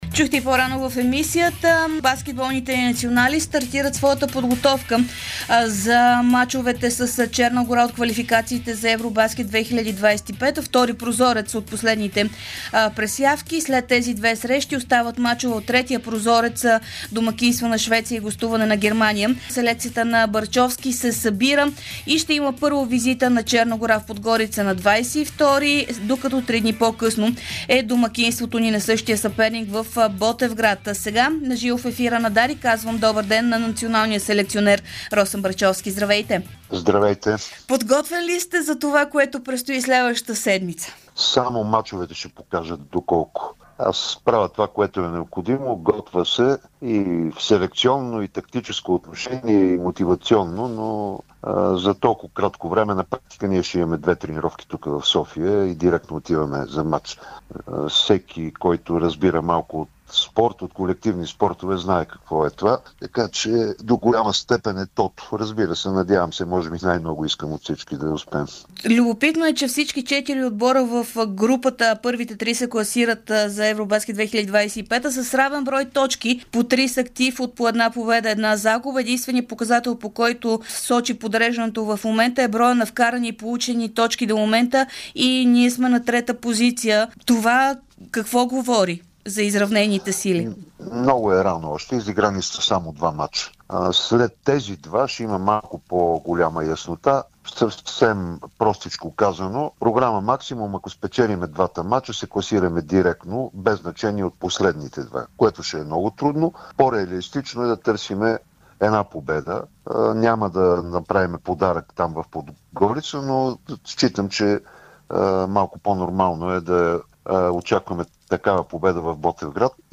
Селекционерът на националния отбор по баскетбол на България – Росен Барчовски, говори ексклузивно пред Дарик радио преди предстоящите два квалификационни мача за ЕвроБаскет 2025 срещу Черна гора.